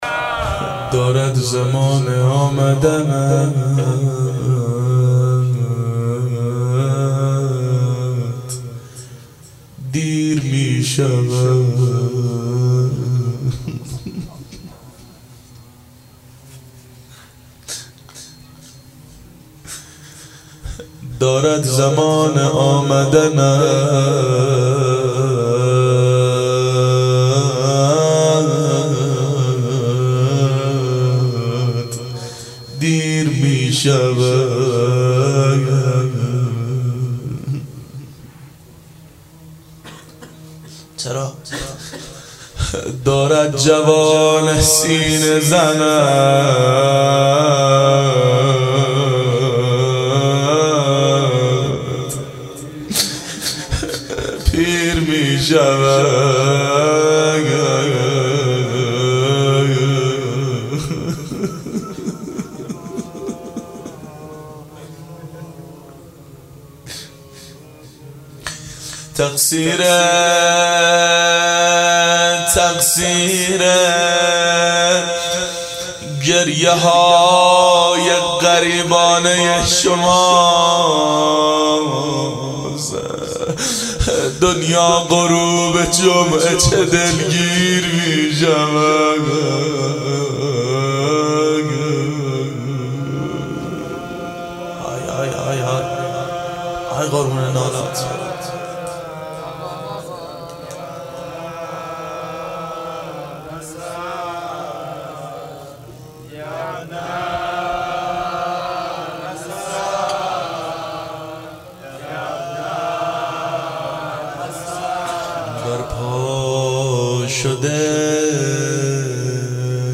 مناجات با امام زمان علیه السلام
• دهه اول صفر سال 1390 هیئت شیفتگان حضرت رقیه س شب سوم (شام غریبان)